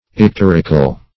Search Result for " icterical" : The Collaborative International Dictionary of English v.0.48: Icteric \Ic*ter"ic\ ([i^]k*t[e^]r"[i^]k), Icterical \Ic*ter"ic*al\ ([i^]k*t[e^]r"[i^]*kal), a. [L. ictericus, Gr.